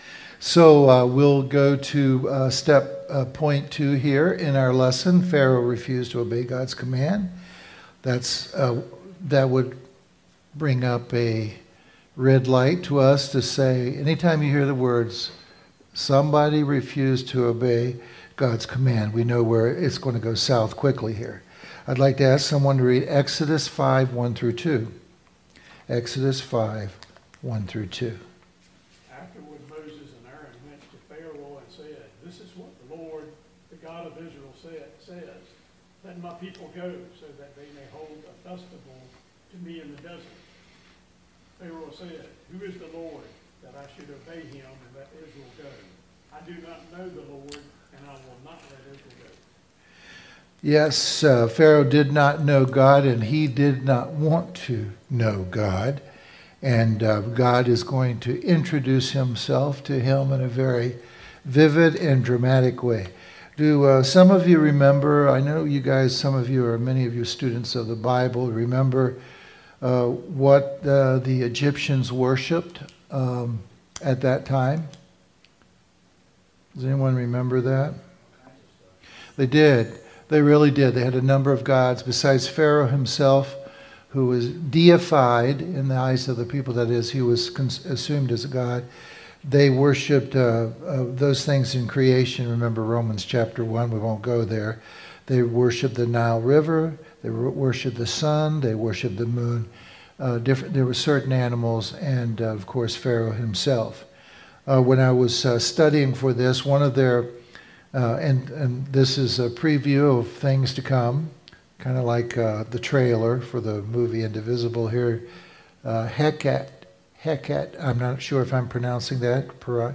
Service Type: Firm Foundations